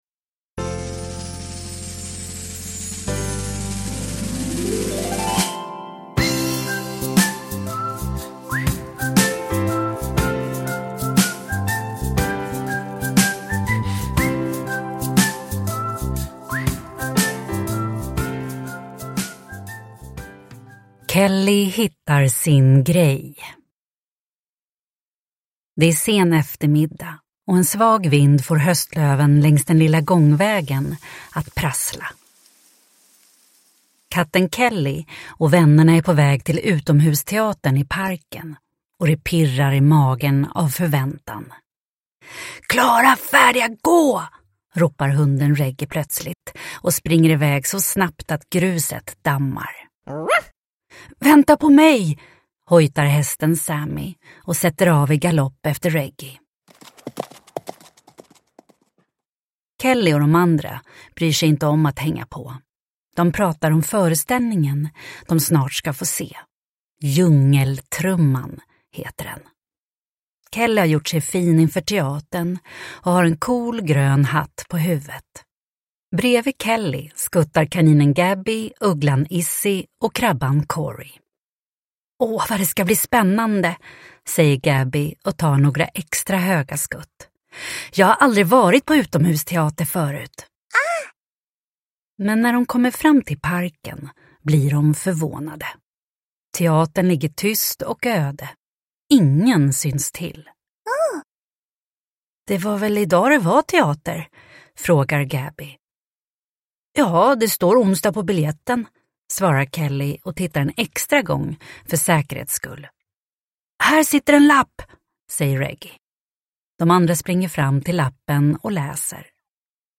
Kelly hittar sin grej – Ljudbok – Laddas ner